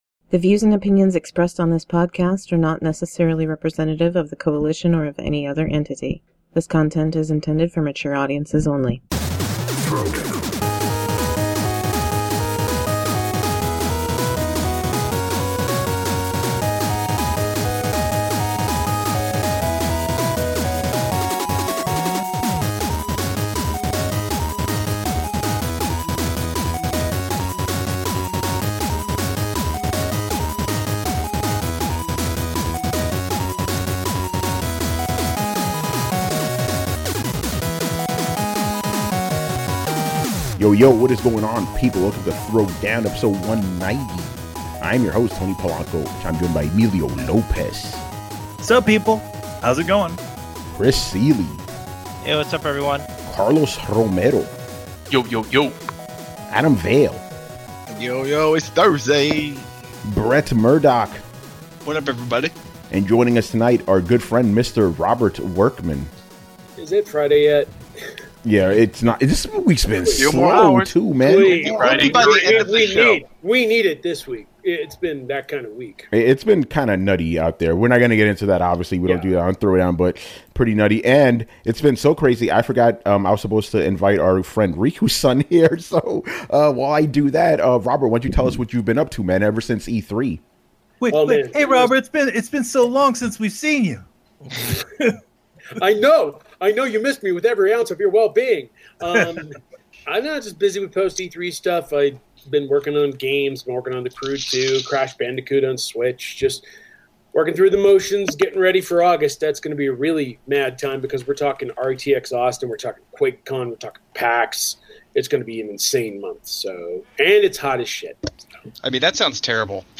On this podcast/vidcast we discuss the latest video game news and topics in an uncompromising and honest manner.